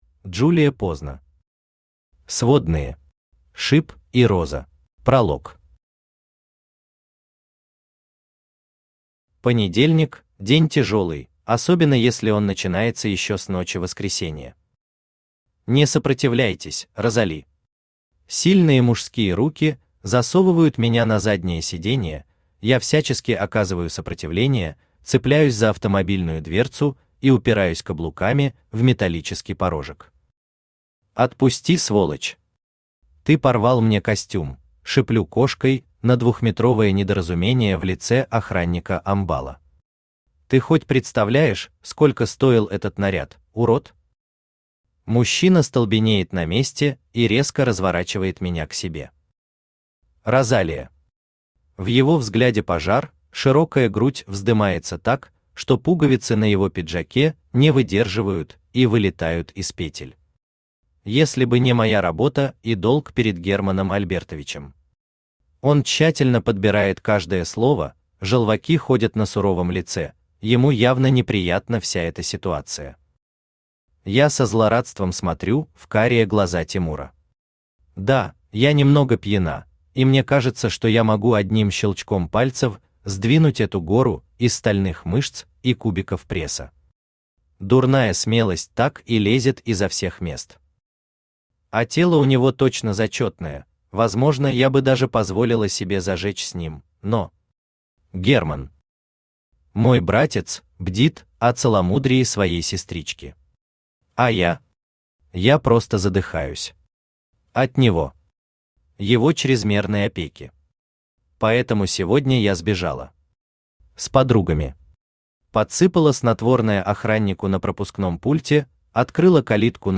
Аудиокнига Сводные. Шип и Роза | Библиотека аудиокниг
Шип и Роза Автор Джулия Поздно Читает аудиокнигу Авточтец ЛитРес.